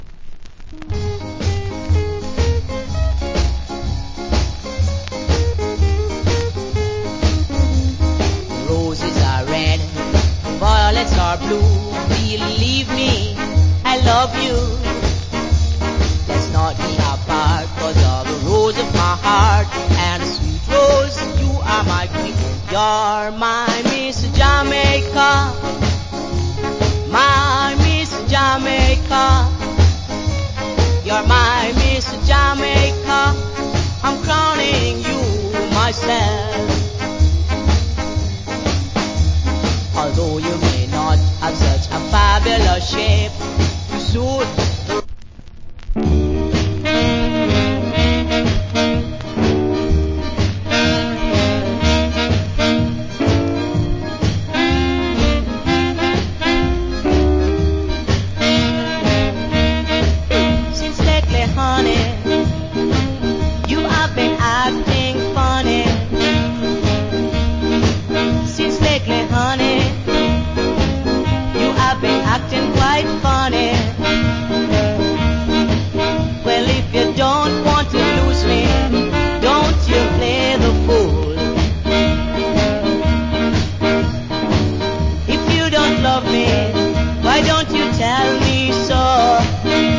Nice Ska Vocal.